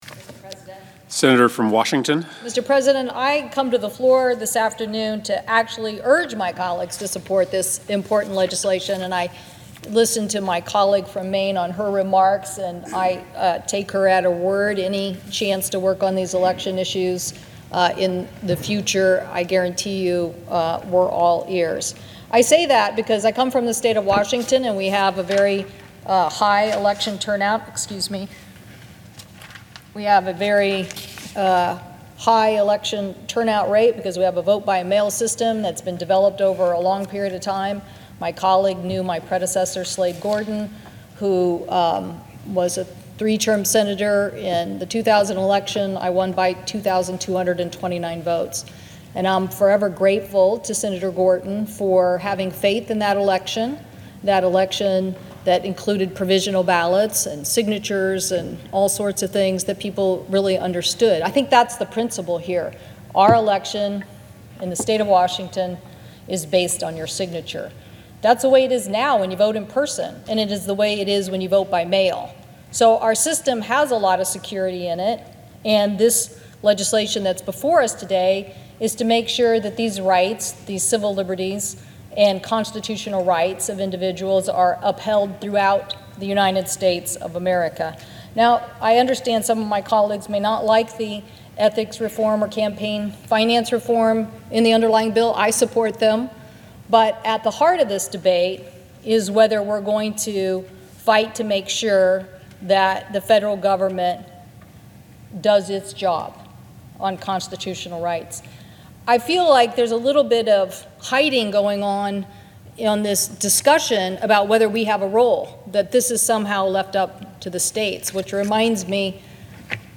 WASHINGTON, D.C. –Today, U.S. Senator Maria Cantwell (D-WA) gave a speech on the Senate Floor in support of the For the People Act to uphold constitutionally protected voting rights. She spoke about the need for the federal government to step in when states fail to act.